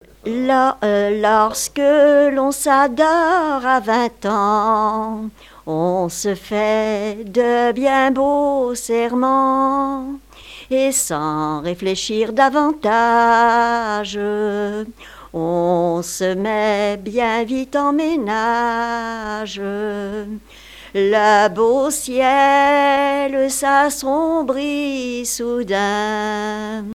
Genre strophique
danses à l'accordéon diatonique et chansons
Pièce musicale inédite